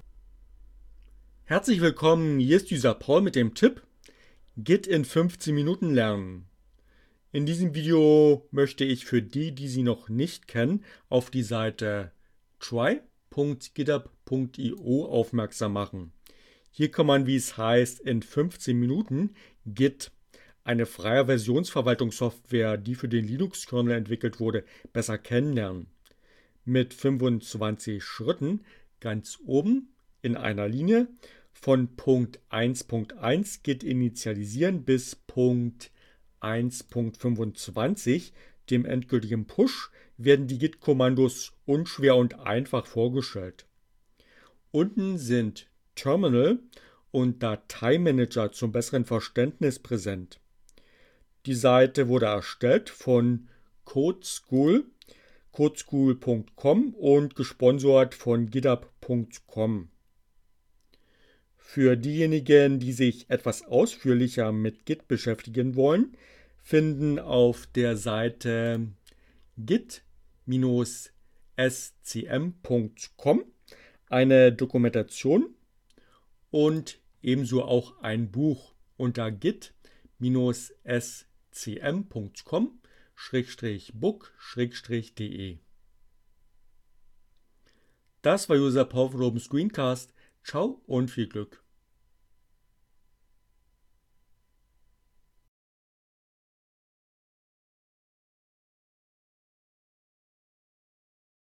Tags: CC by, Linux, Neueinsteiger, ohne Musik, screencast, git, Web